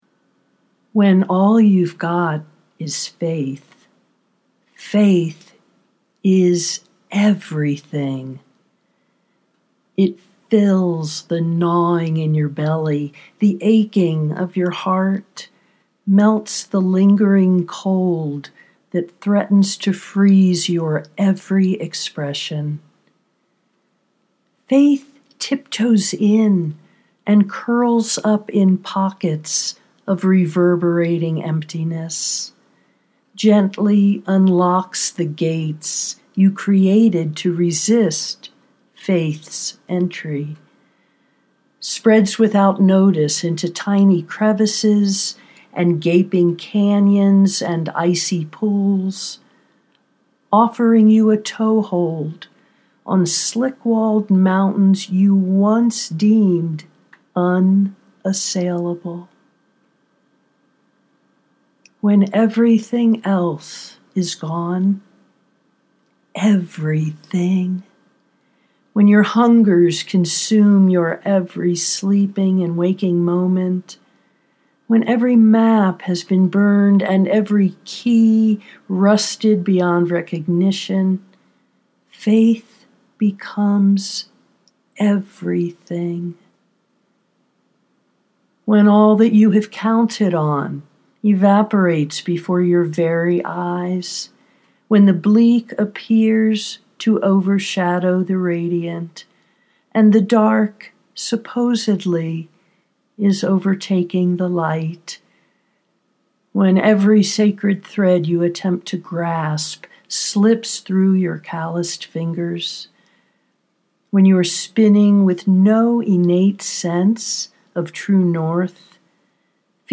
when all you’ve got is faith…(audio poetry 3:25)